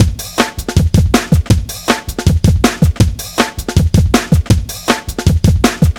Index of /90_sSampleCDs/Zero-G - Total Drum Bass/Drumloops - 1/track 11 (160bpm)